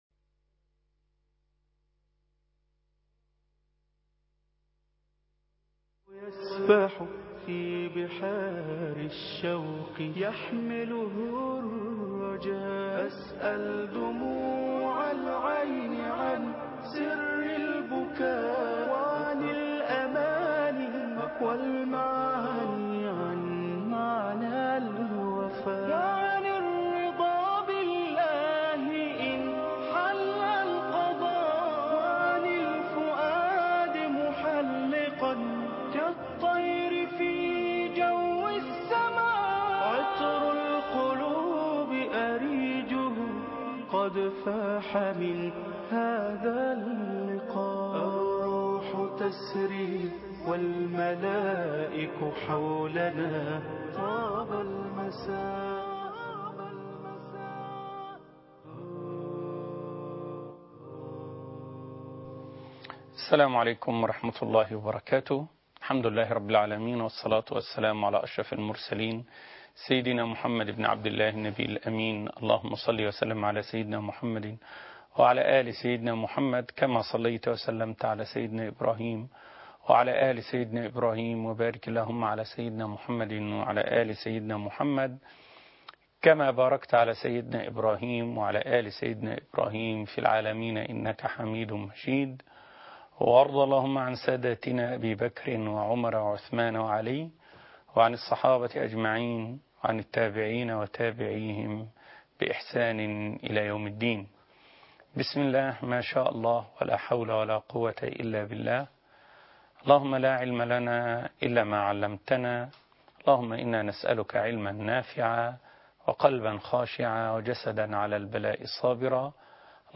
حلقة خاصة ولقاء مع هشام الجخ ( 10/6/2012 ) فضفضة - قسم المنوعات